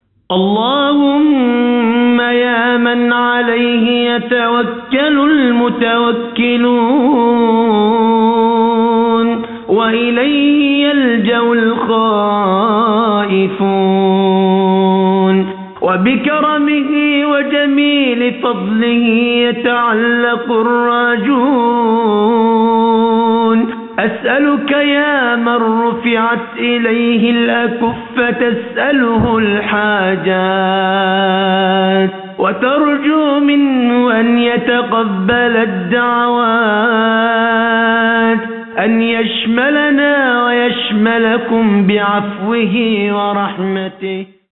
ادعية